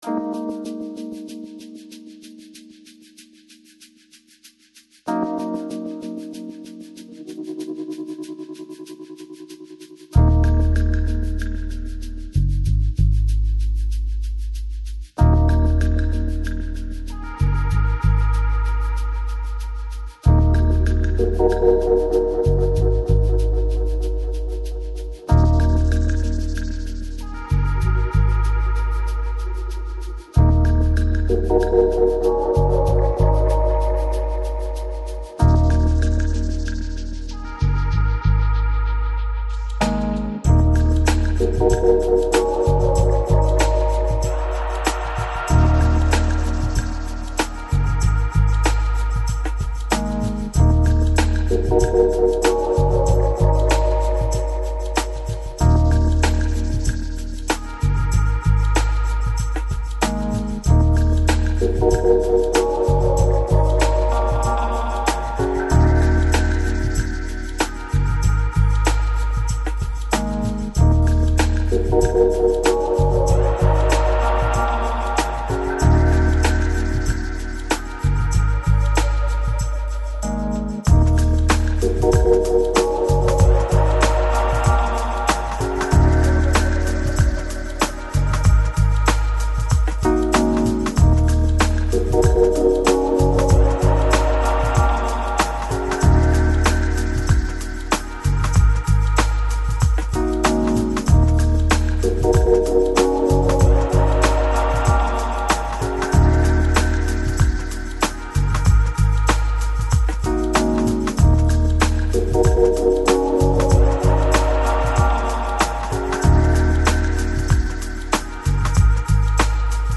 Жанр: Lounge, Chill Out